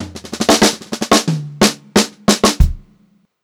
92ST2FILL2-L.wav